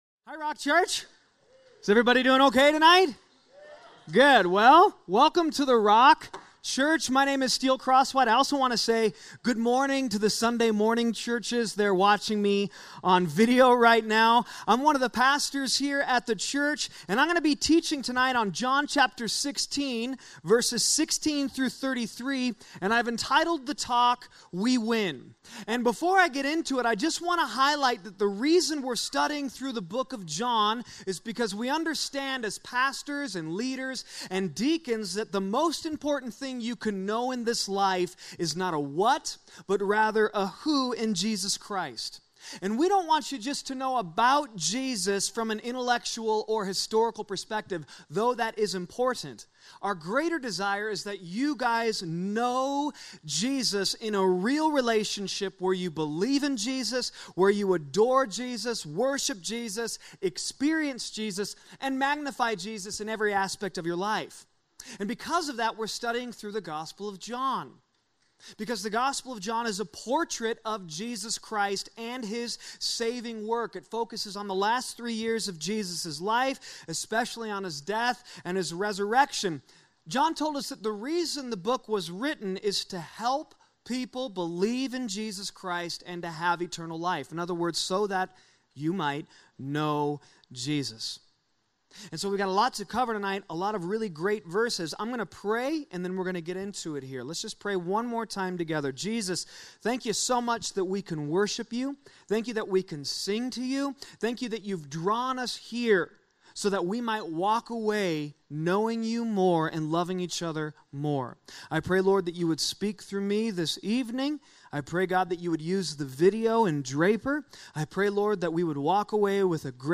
A message from the series "David."